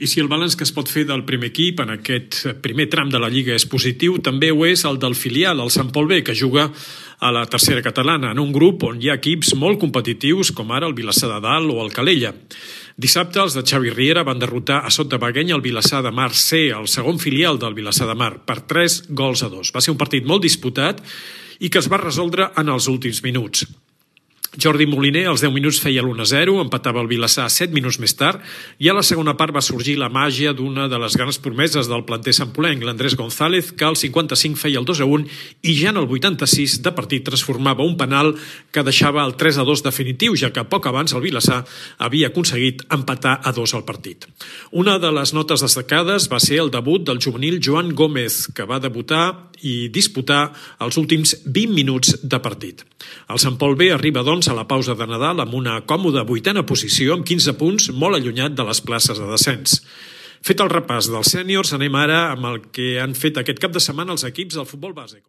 7986a1bfaaf348f170d8a369e0aa74e455abdf5b.mp3 Títol Ràdio Sant Pol Emissora Ràdio Sant Pol Titularitat Pública municipal Nom programa Club 19:20 Descripció Crònica del partit de futbol Sant Pol B-Vilassar.